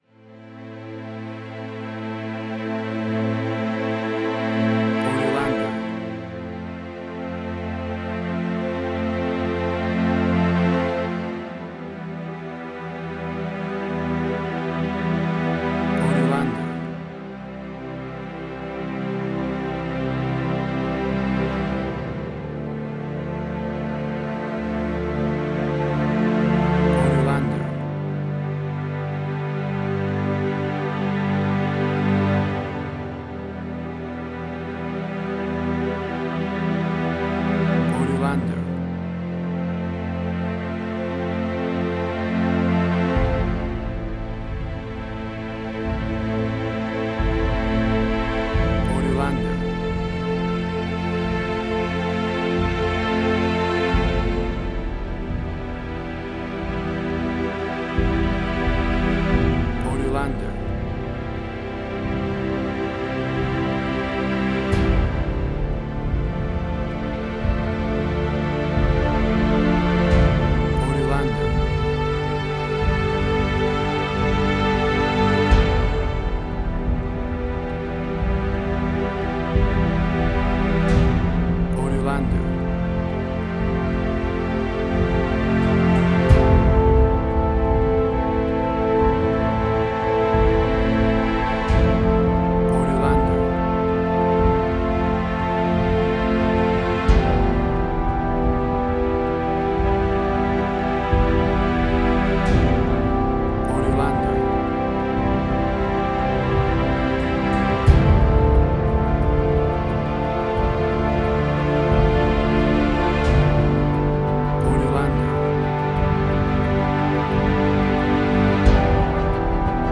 Modern Science Fiction Film
Tempo (BPM): 89